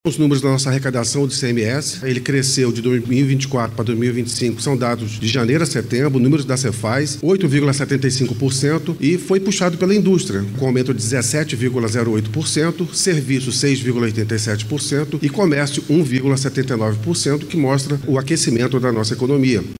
O secretário executivo de Desenvolvimento, Gustavo Igrejas, ressaltou o crescimento na arrecadação do Estado.